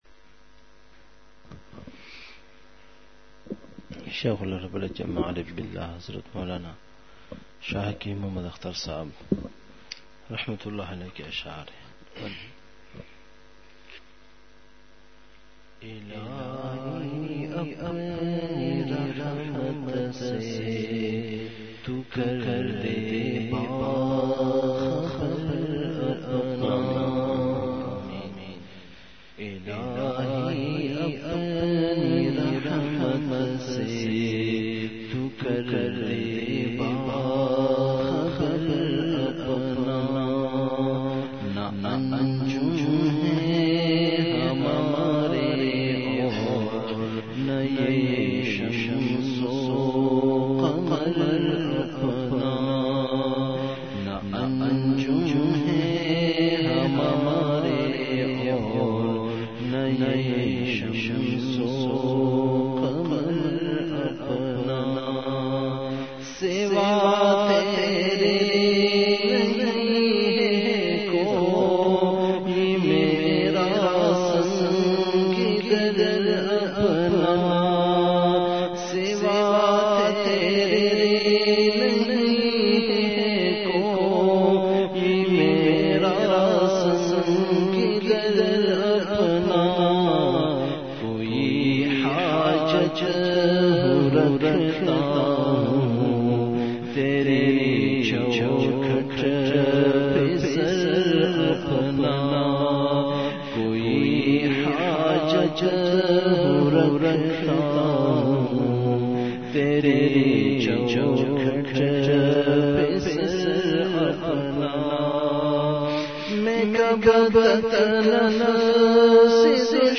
اصلاحی مجلس کی جھلکیاں *مقام:مسجد اختر نزد سندھ بلوچ سوسائٹی گلستانِ جوہر کراچی*